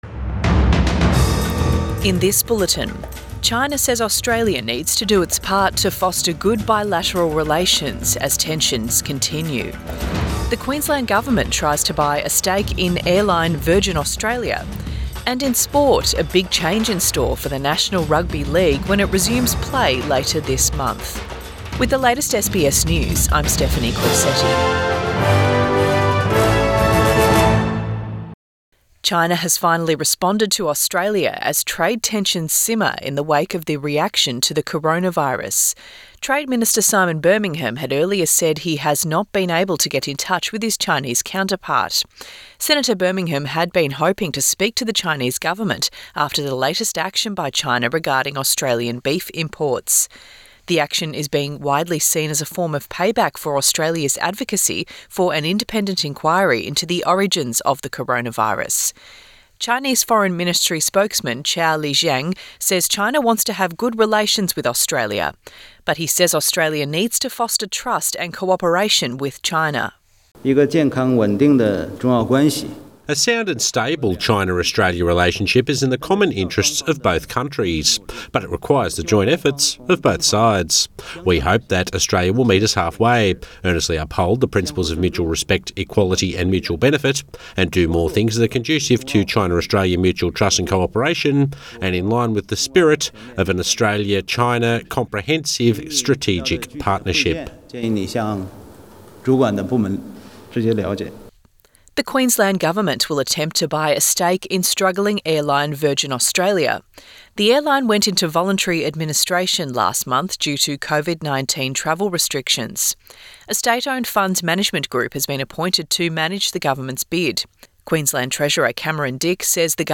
AM bulletin 14 May 2020